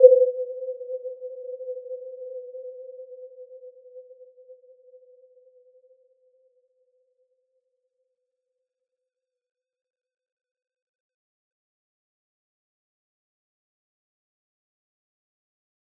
Warm-Bounce-C5-f.wav